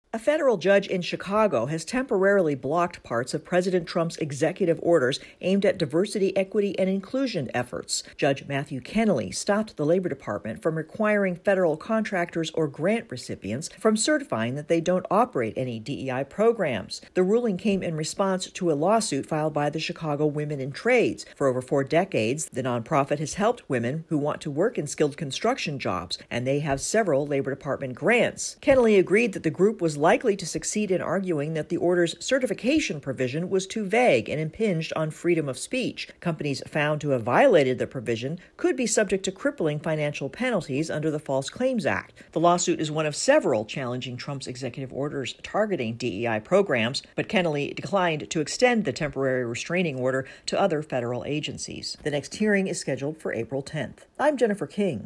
A federal judge in Chicago has paused enforcement of parts of President Trump's anti-DEI initiative. AP correspondent